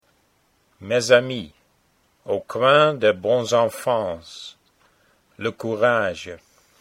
The names of establishments in the section below are mostly French, and pronounced somewhat in that manner (Reitz is German.)